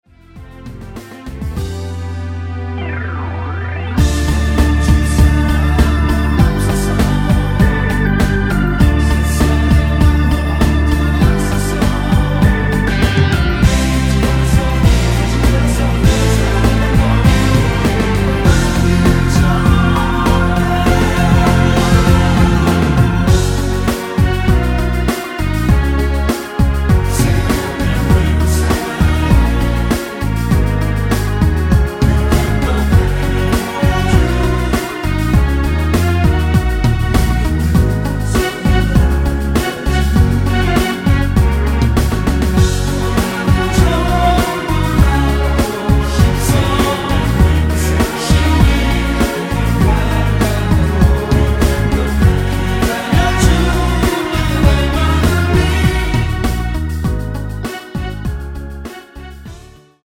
(-2) 내린 코러스 포함된 MR 입니다.(미리듣기 참조)
앞부분30초, 뒷부분30초씩 편집해서 올려 드리고 있습니다.